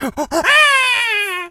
monkey_hurt_scream_03.wav